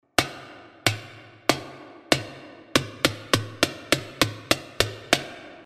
claqué.mp3